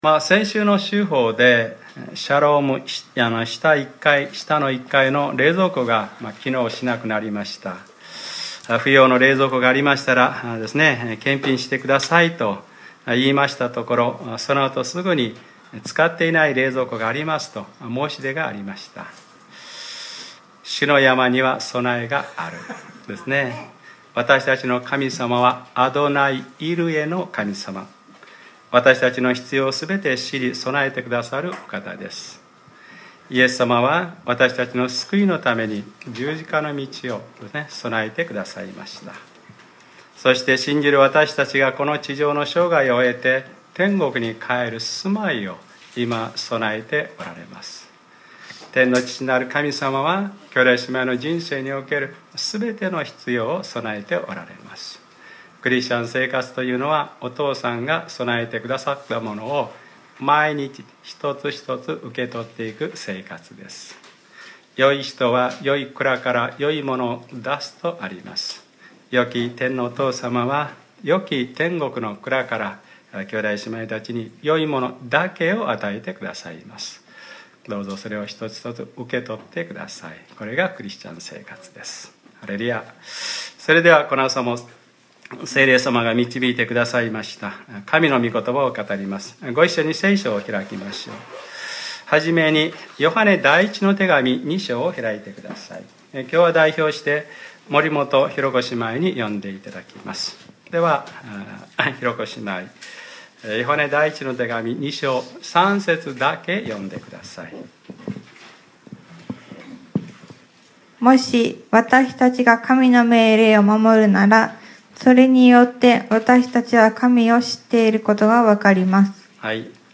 2015年04月26日）礼拝説教 『Ⅰヨハネｰ３：古くて新しい命令』